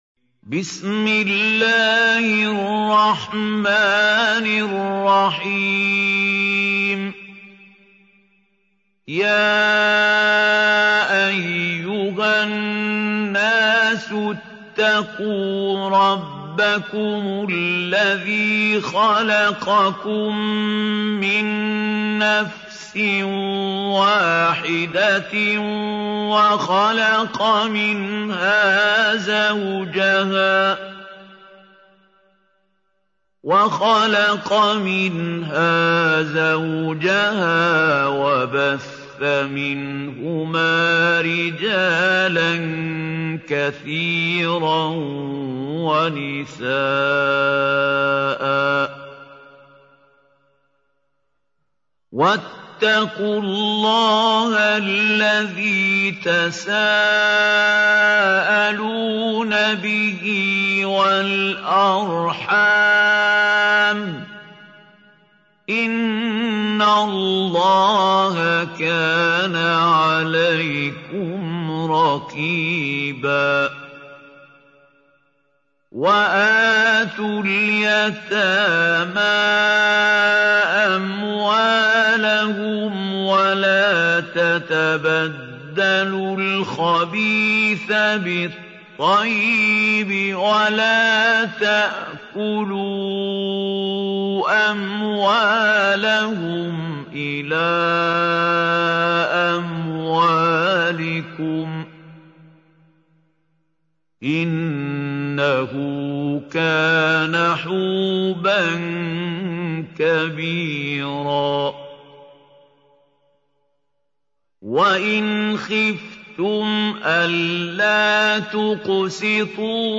Surah An-Nisa Recitation by Mahmoud Al Hussary
Surah Nisa (The Women) is 4rth chapter of Holy Quran. Listen this beautiful Surah in the voice of Mahmoud Khalil Al Hussary.